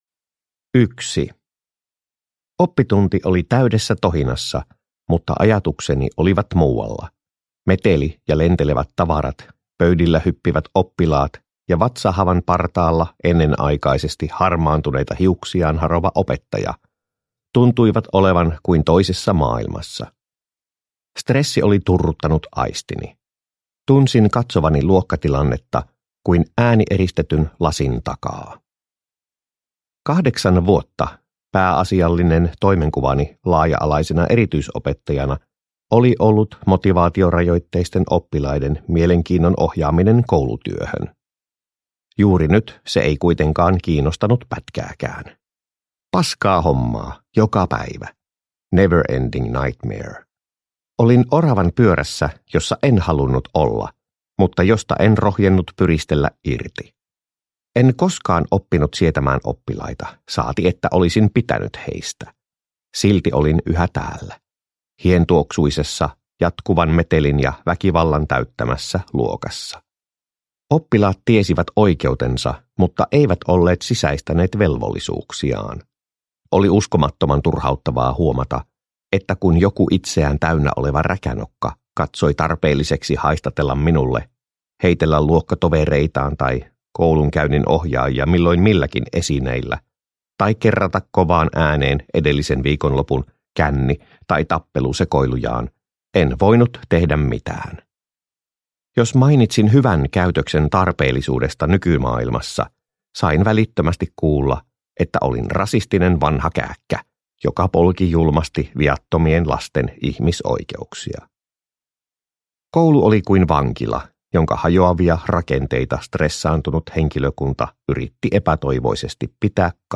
Vihreän Siirtymän kultti – Ljudbok